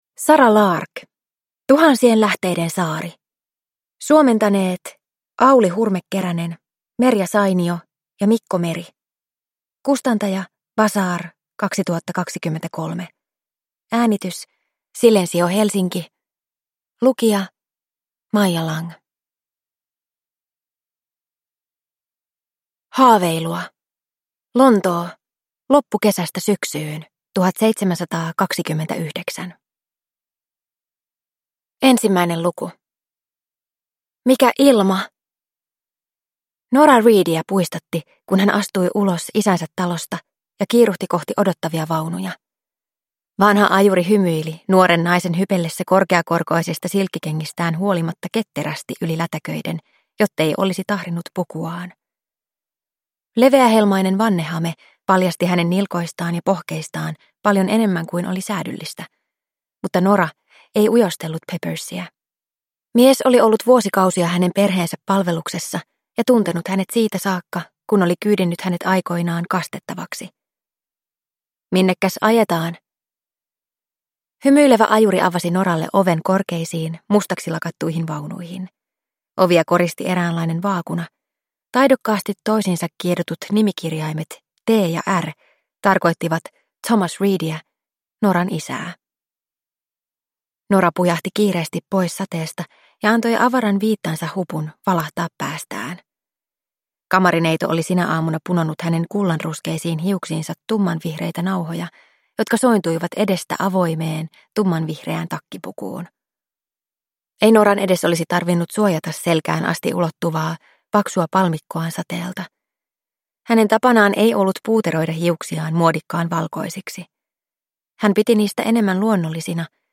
Tuhansien lähteiden saari – Ljudbok – Laddas ner